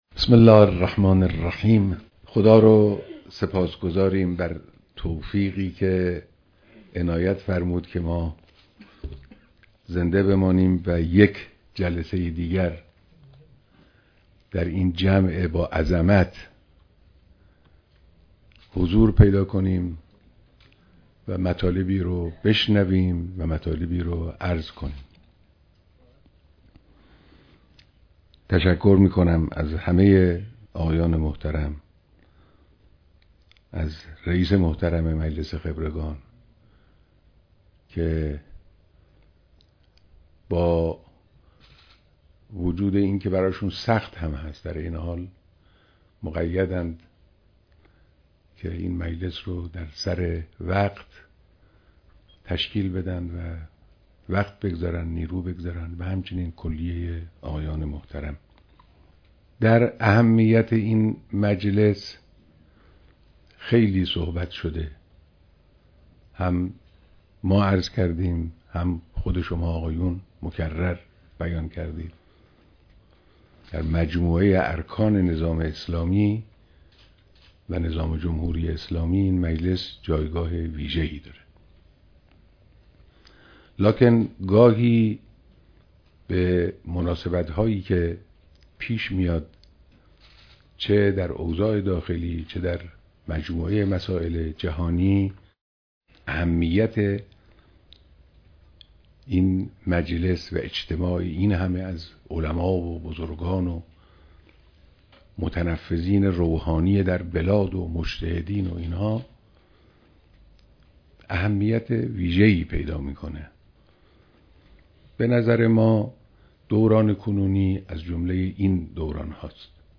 بیانات در دیدار رئیس و اعضای مجلس خبرگان رهبری